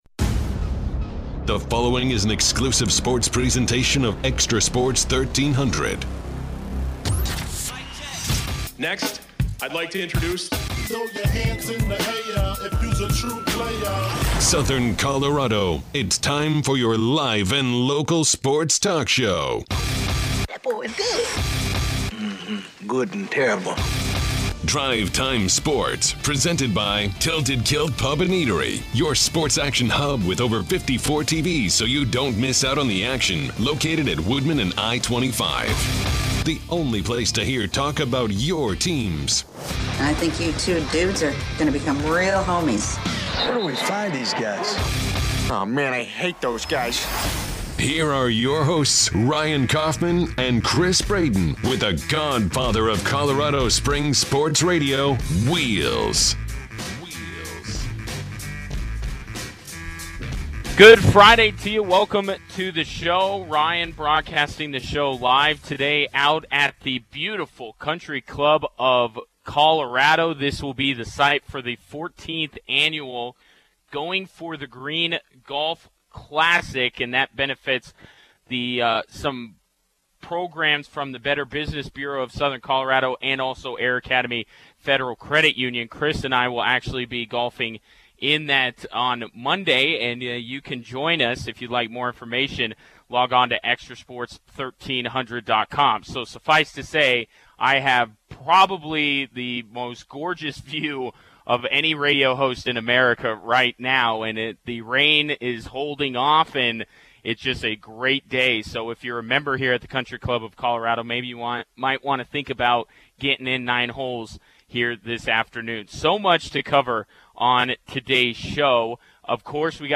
broadcasts live from The Country Club of Colorado